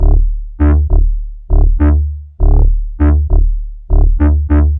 Dance music bass loops 2
Dance music bass loop - 100bpm 49